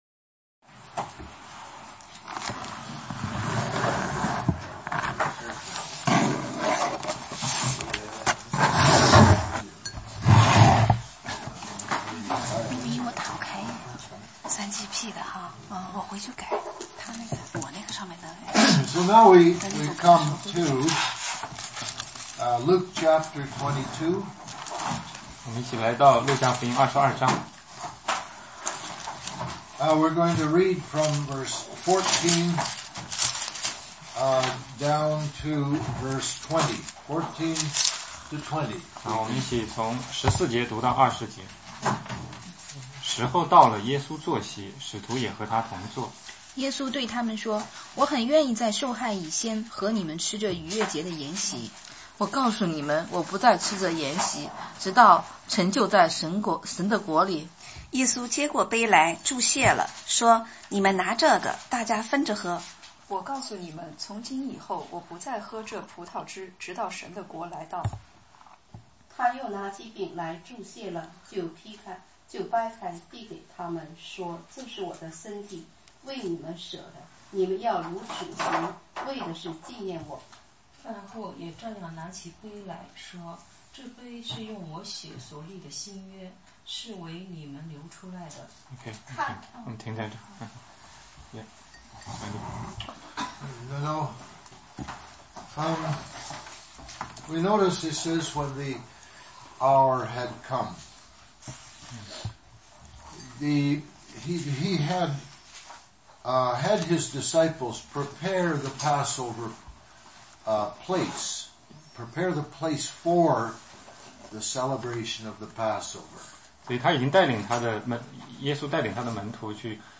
16街讲道录音 - 路加福音